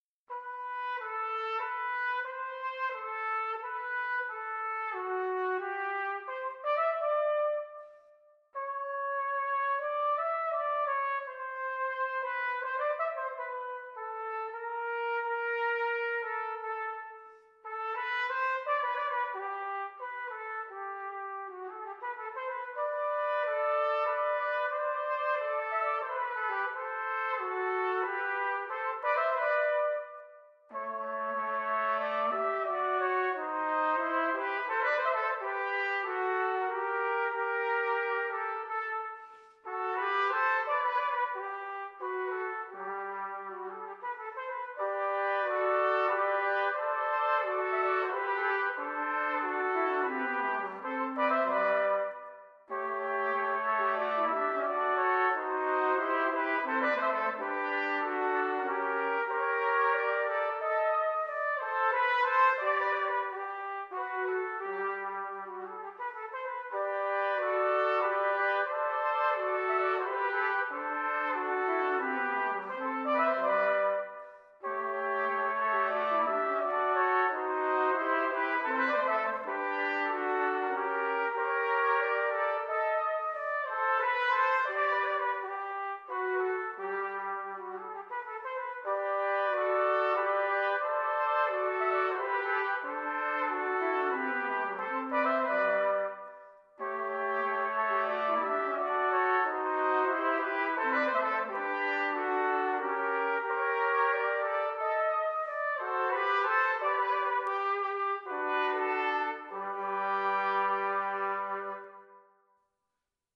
Classical (View more Classical Choir Music)
3 B-Flat Trumpets